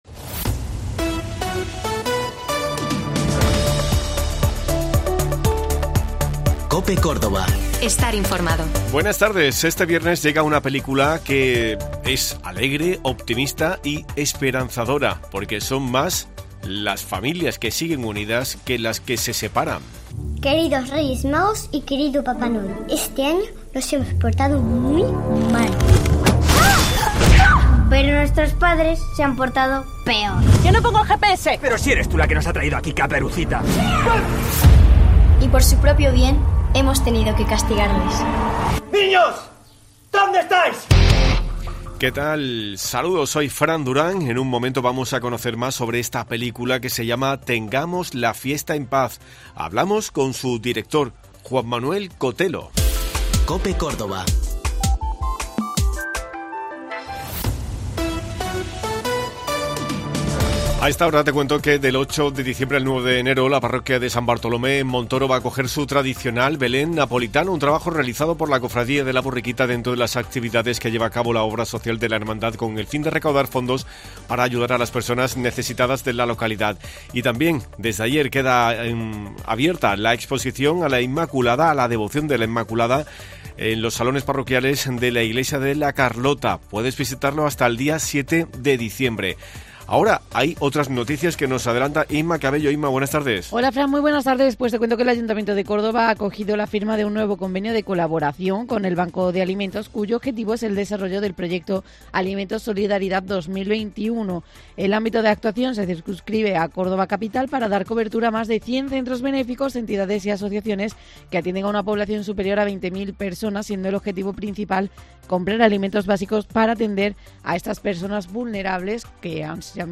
Hemos hablado con su director sobre lo que pretenden con esta película en este tiempo donde los valores no existen y no se potencia a la familia.